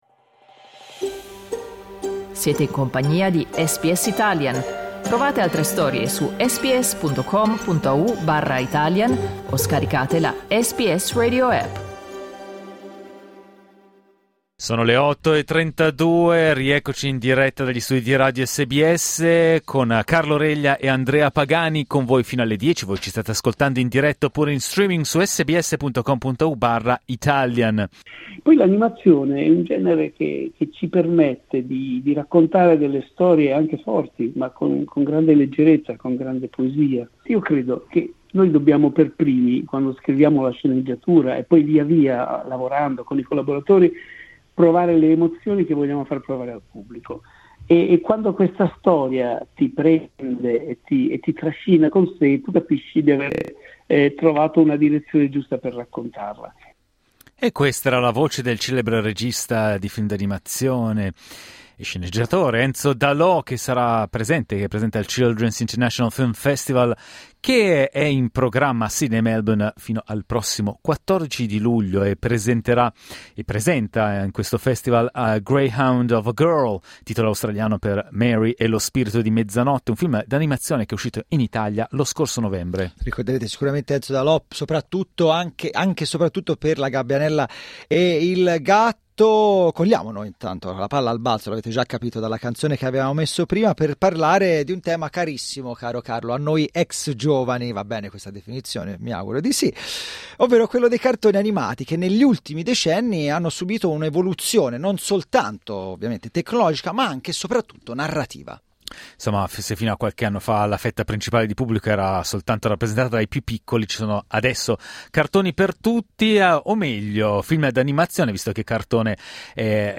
Clicca sul tasto "play" in alto per ascoltare il dibattito